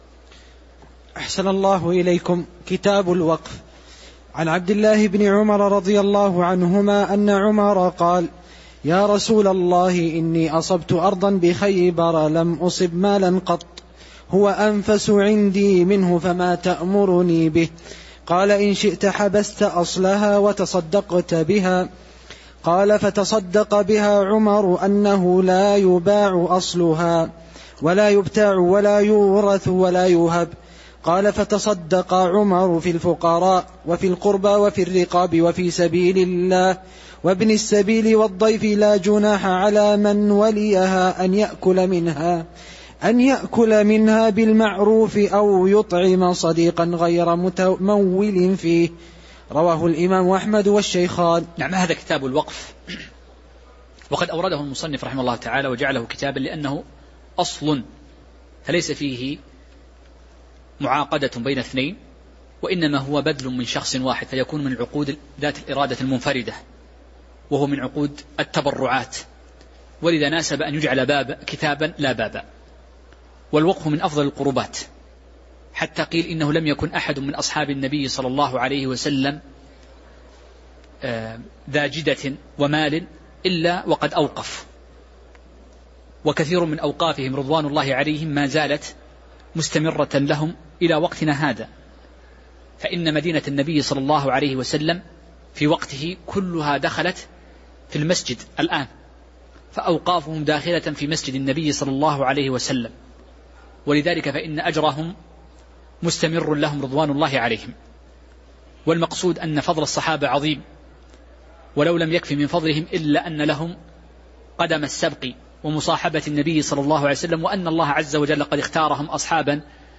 تاريخ النشر ١ شعبان ١٤٤٠ هـ المكان: المسجد النبوي الشيخ